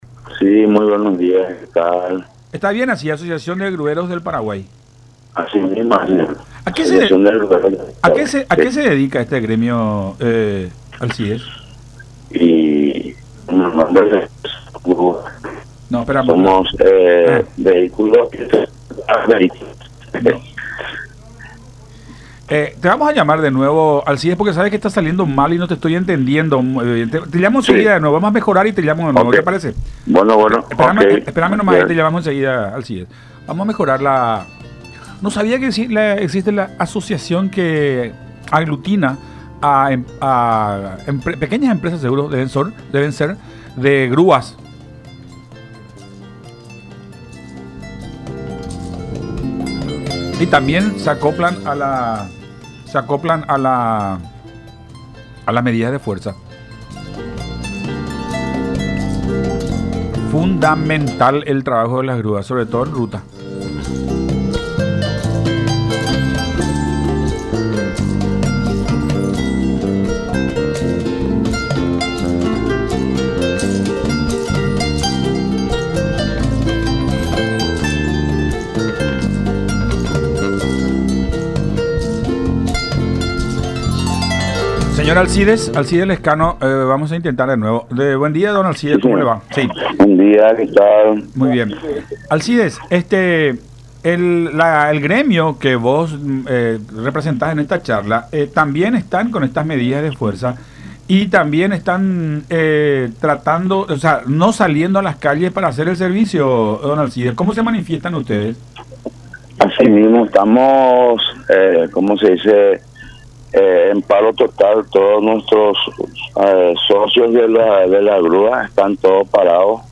en diálogo con Todas Las Voces por La Unión.